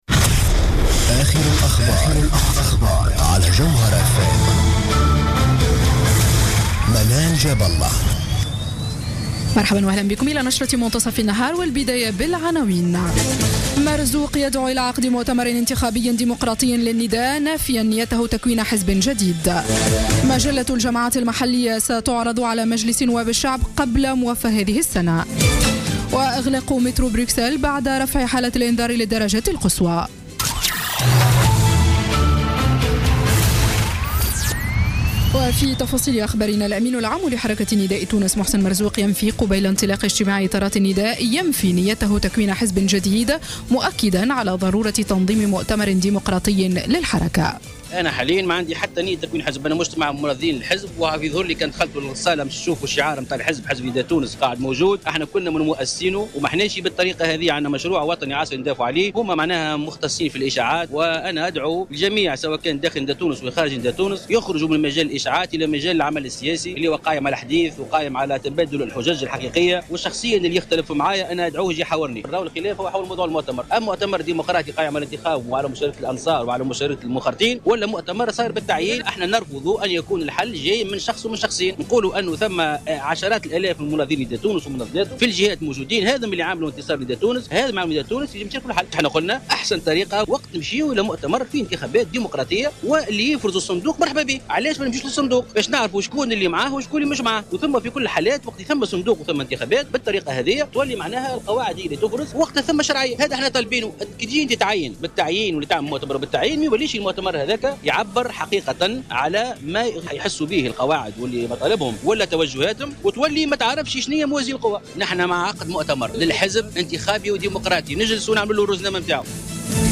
نشرة أخبار منتصف النهار ليوم السبت 21 نوفمبر 2015